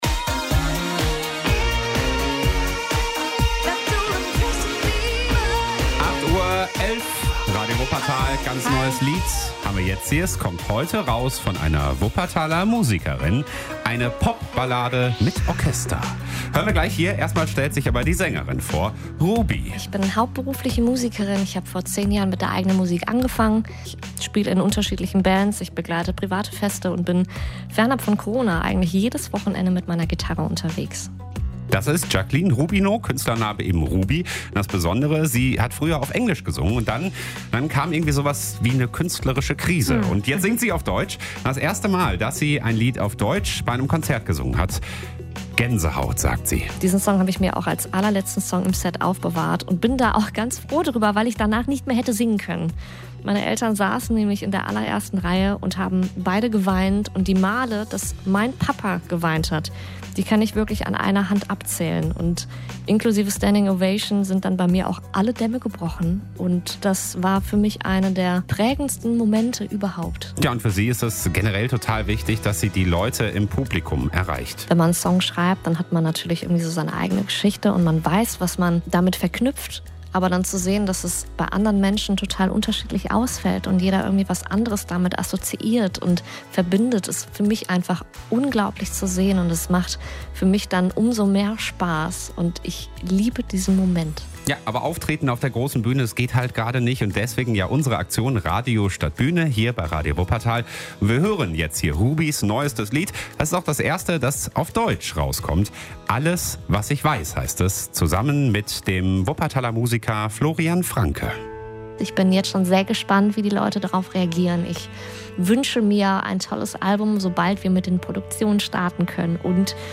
Pop.